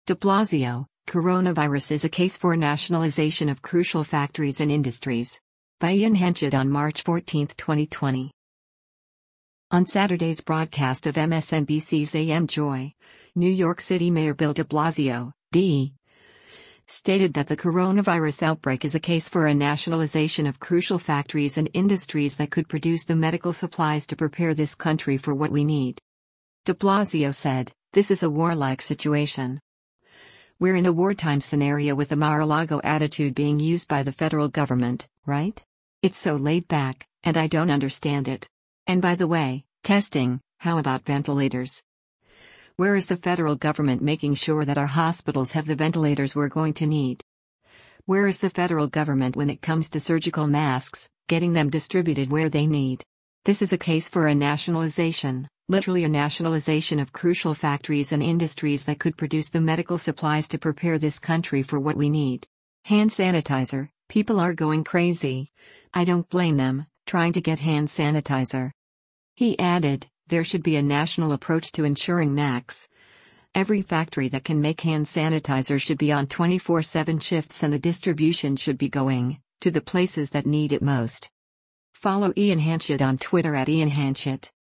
On Saturday’s broadcast of MSNBC’s “AM Joy,” New York City Mayor Bill de Blasio (D) stated that the coronavirus outbreak is a case for “a nationalization of crucial factories and industries that could produce the medical supplies to prepare this country for what we need.”